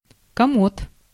Ääntäminen
US : IPA : [ˈbjʊə.rəʊ]